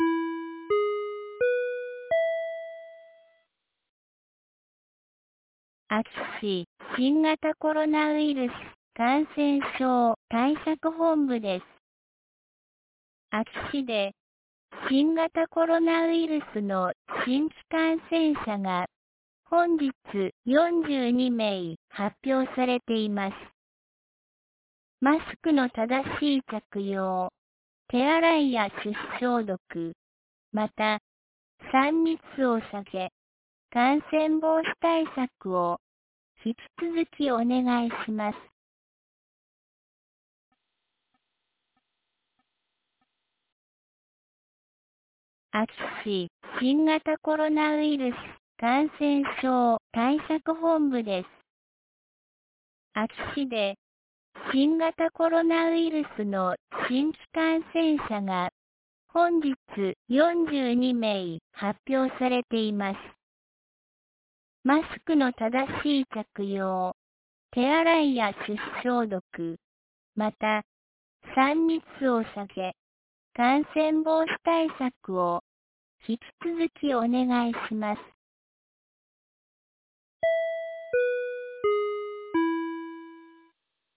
2022年08月13日 17時06分に、安芸市より全地区へ放送がありました。